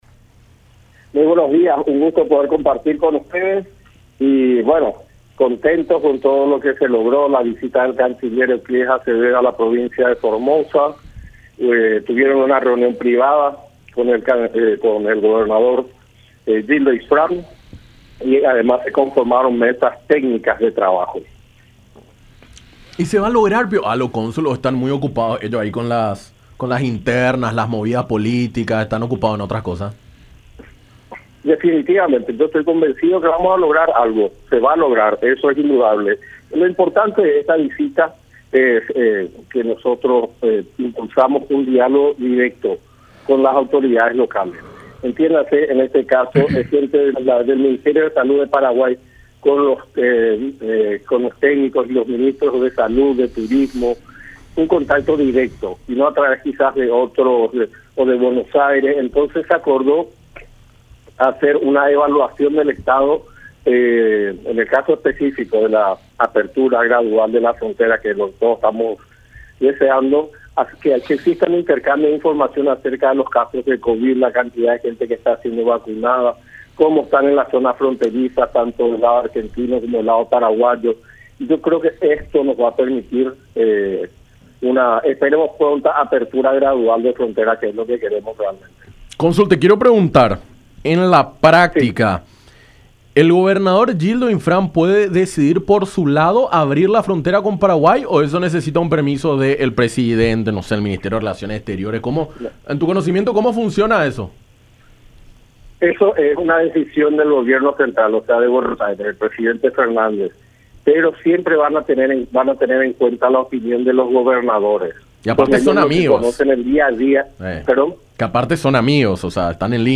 Lo importante de esto es que se ha avanzado con las autoridades locales en el intercambio de información sobre la actualización de los casos del COVID-19”, dijo Acosta en diálogo con Enfoque 800 por La Unión.